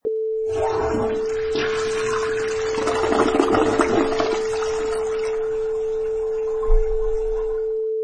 Clips: Toilet Flush
High quality recording of a household toilet flush
Product Info: 48k 24bit Stereo
Category: Household / Bathroom - Toilets
Try preview above (pink tone added for copyright).
Toilet_Flush.mp3